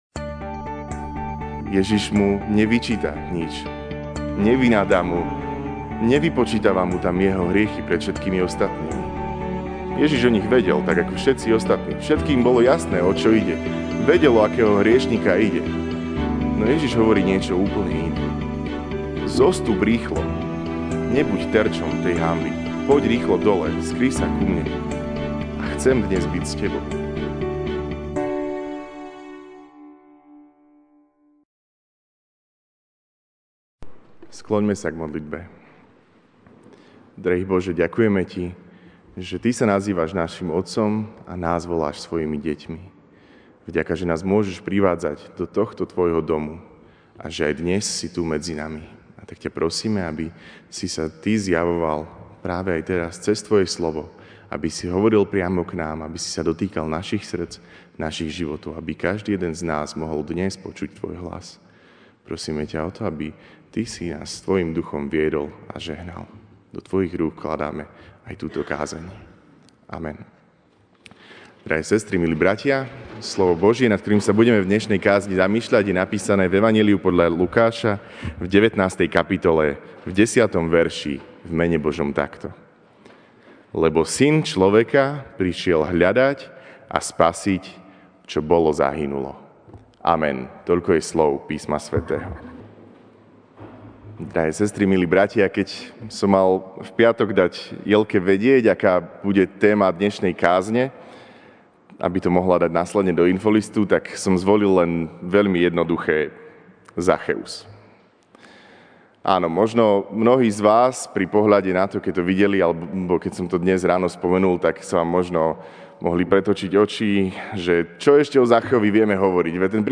jún 30, 2019 Zacheus MP3 SUBSCRIBE on iTunes(Podcast) Notes Sermons in this Series Ranná kázeň: Zacheus (L 19, 10) Lebo Syn človeka prišiel hľadať a spasiť, čo bolo zahynulo.